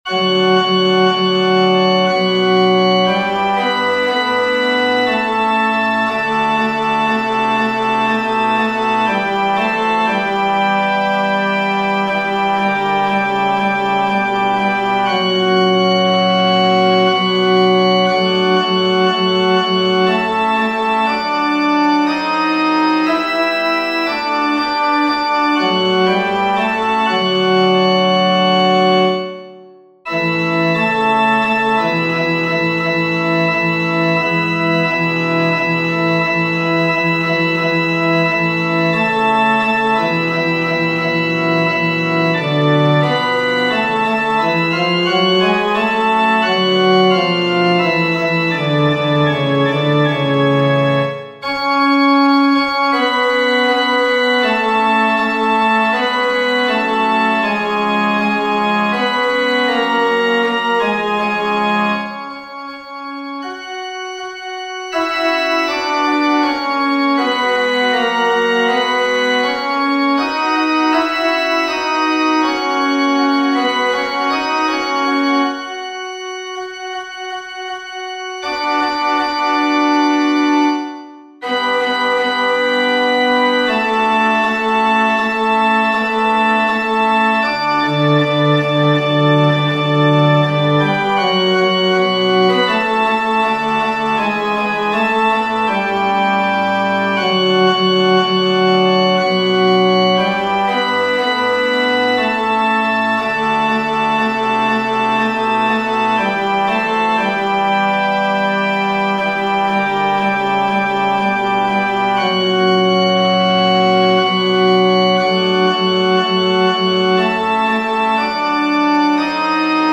FF:HV_15b Collegium musicum - mužský sbor
Veno-baryton.mp3